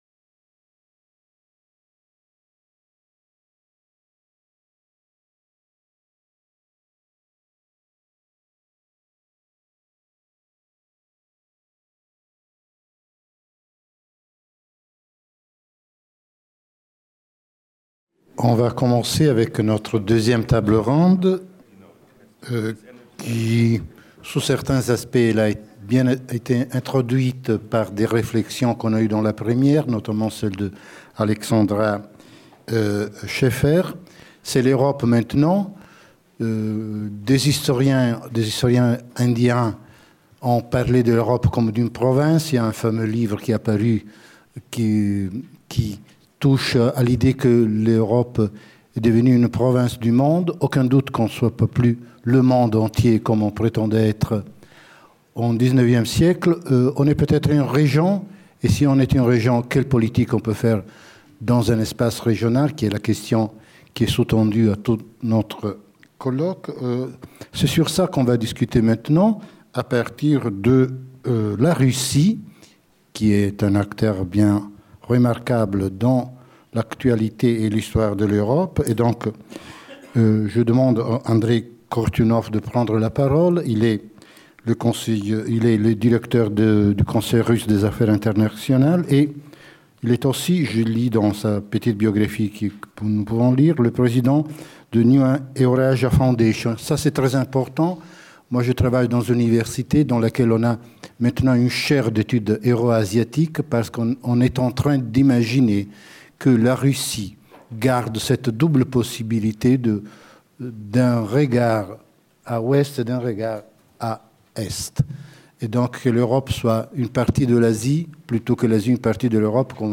Penser l’équilibre régional pour rebâtir un système global ? Table ronde 2 – Quel ordre régional en Europe ?
Ce séminaire, organisé en partenariat avec Radio France Internationale, aura lieu à l'EHESS, 105 boulevard Raspail, 75006 Paris, Amphithéâtre François Furet